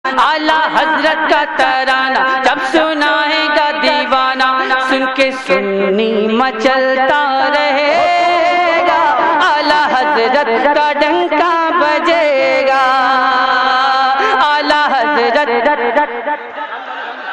• Качество: 128, Stereo
мужской голос
восточные мотивы
спокойные
арабские